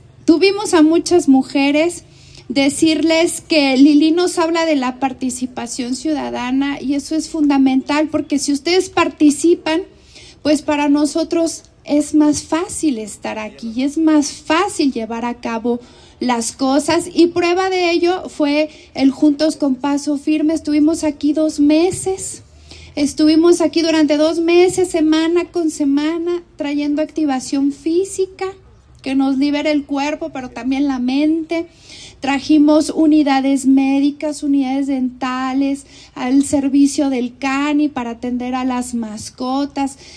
Yendy Cortinas López, directora de CODE Guanajuato